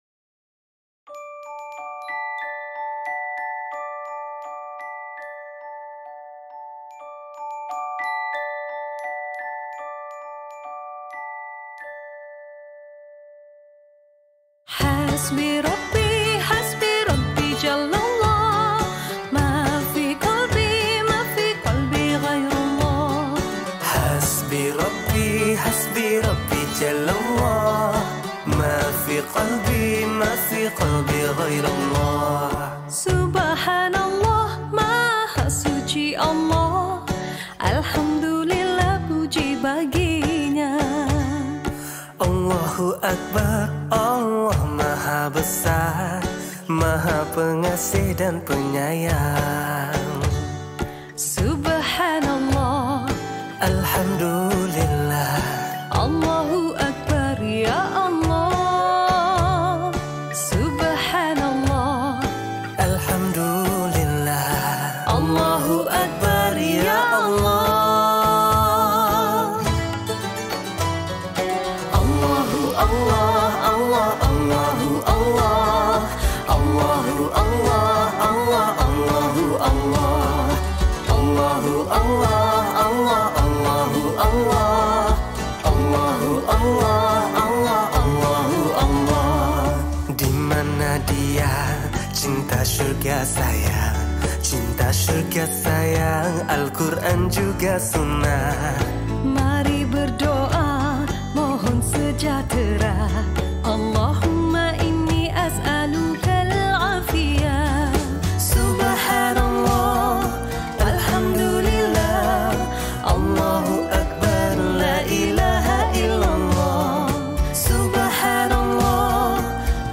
Nasyid Songs